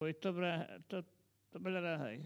Collectif atelier de patois
Catégorie Locution